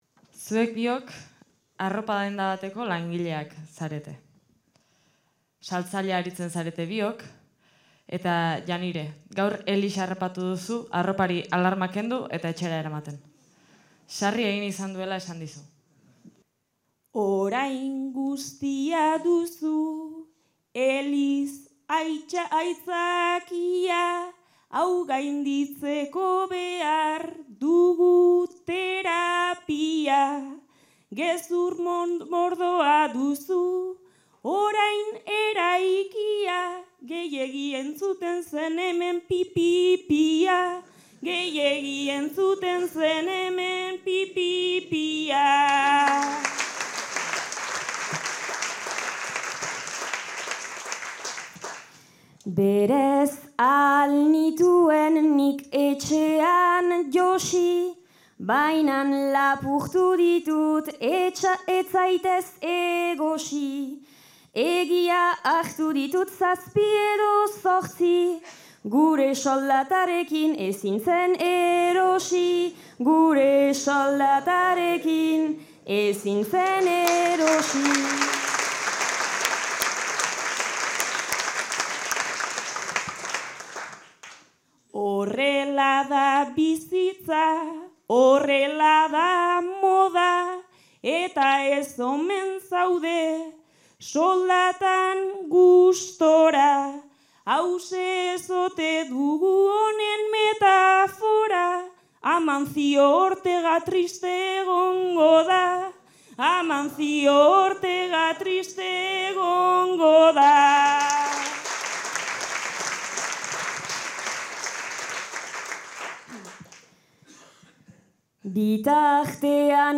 Zarautz (Gipuzkoa)
Zortziko txikia.